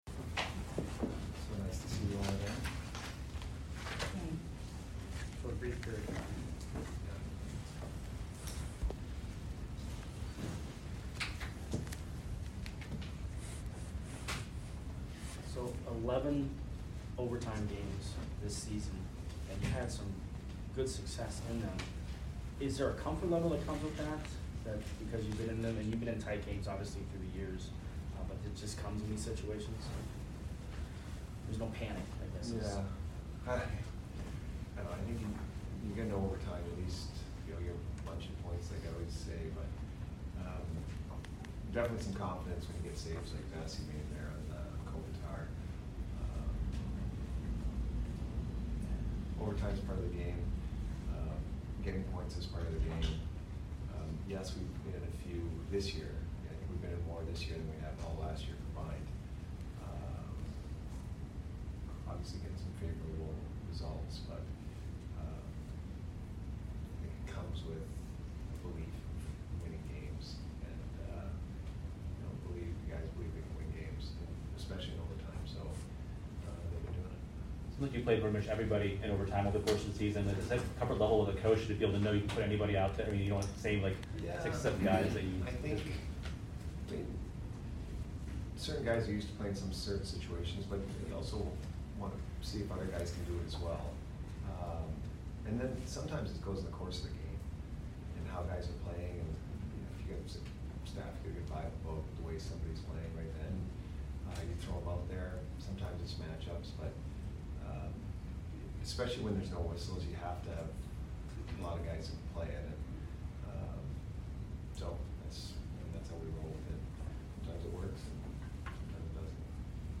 Head Coach Jon Cooper Post Game Vs LAK 12 - 14 - 21